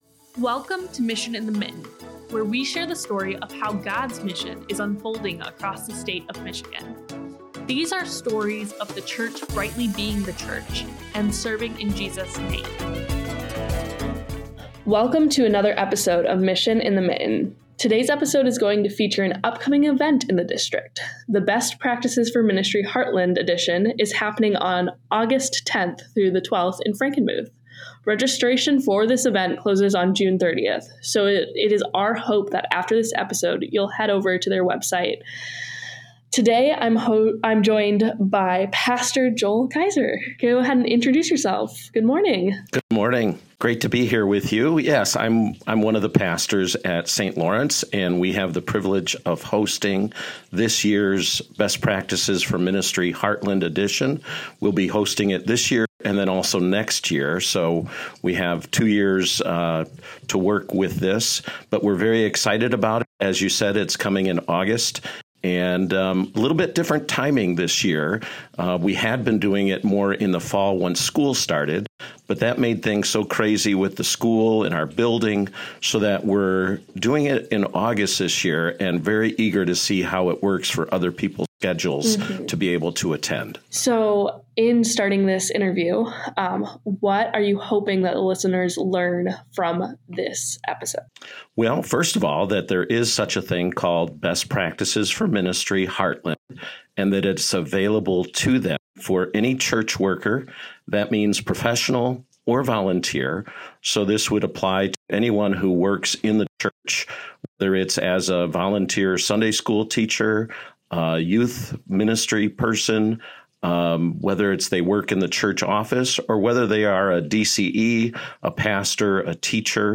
A discussion on the upcoming Best Practices for Ministry Heartland conference. Listen and explore the significance of attending this event.